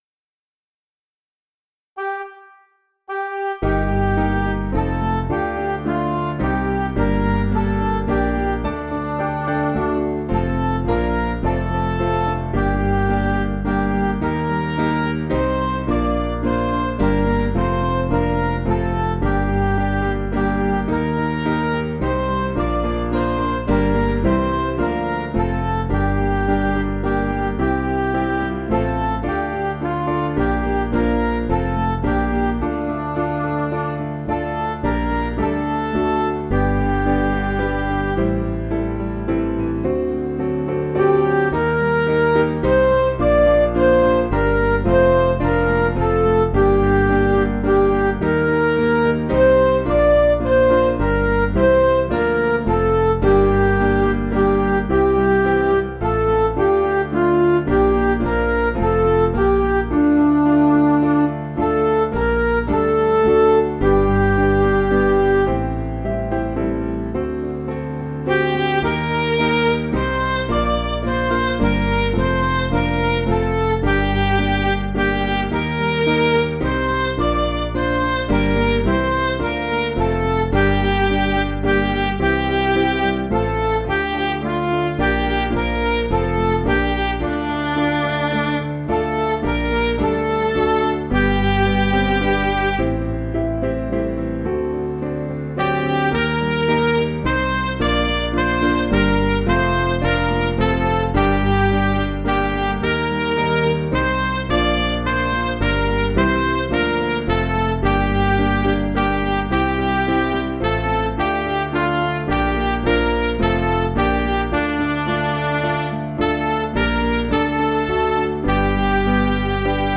Piano & Instrumental
(CM) 4/Gm
Single note leadin